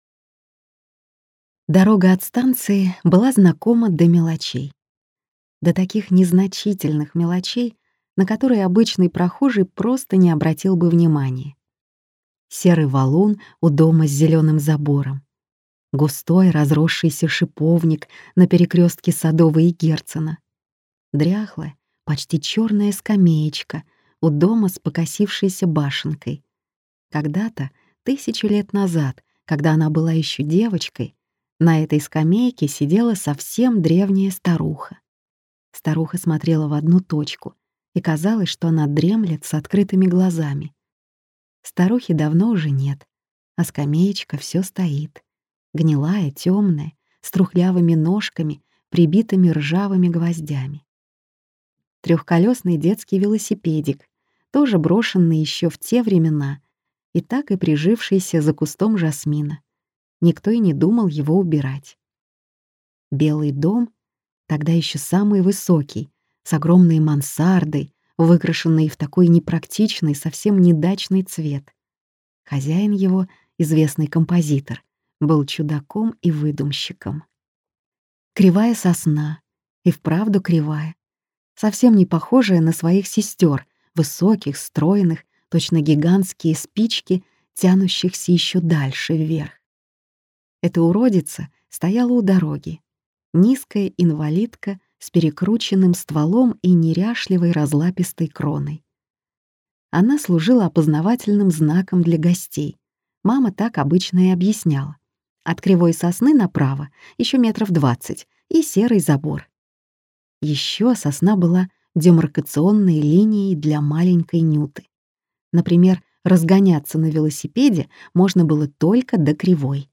Аудиокнига Вечный запах флоксов | Библиотека аудиокниг